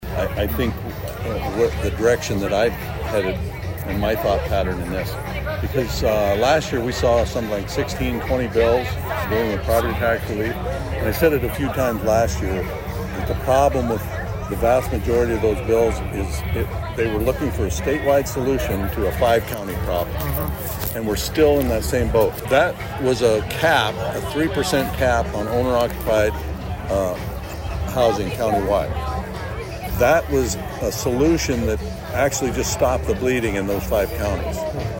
Prior to that Governor Rhoden did an interview with HubCityRadio to address a variety of topics.